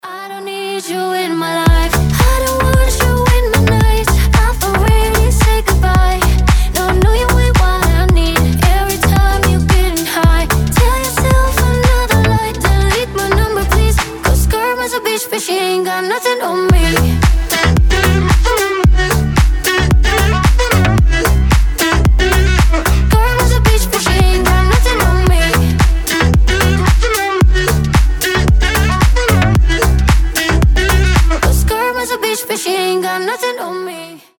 • Качество: 320, Stereo
Dance Pop
красивый женский голос